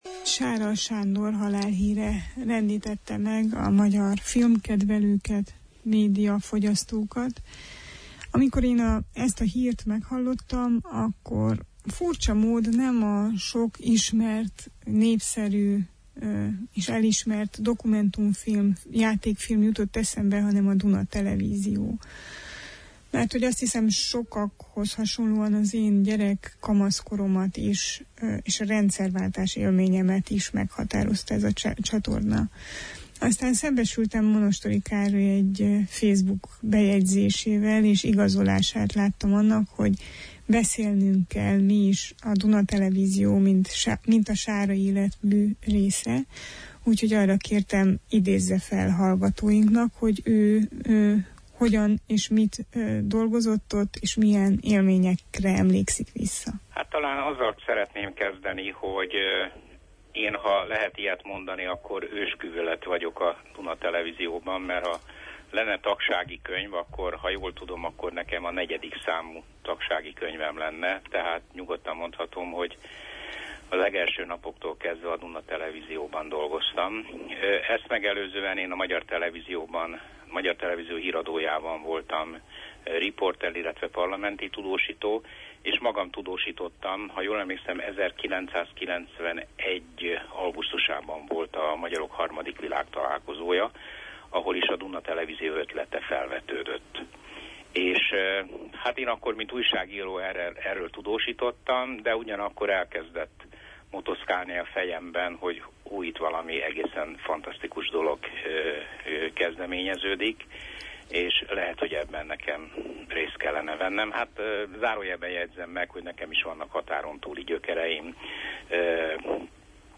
Arról beszélgettünk, mit jelentett ez a televízió az indulásakor, mi volt a Sára-koncepció, és mit sikerült ebből megvalósítani az első évtizedben, a Sára-érában.